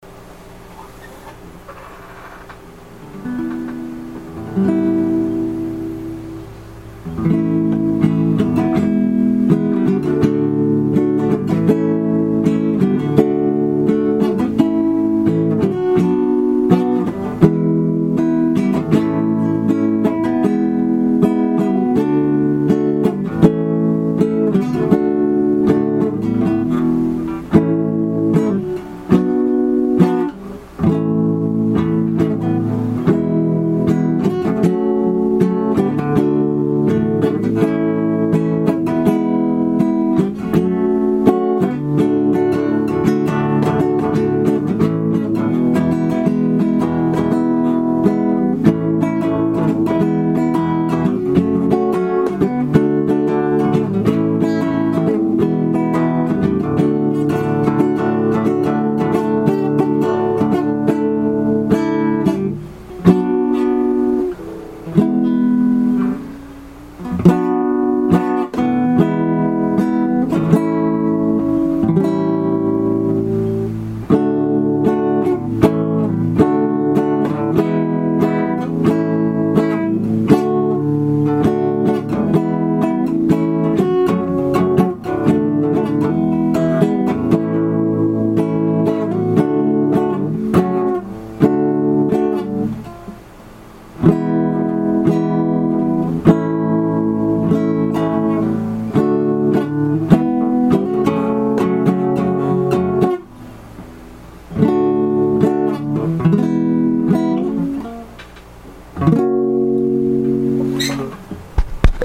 Just some Guitar sounds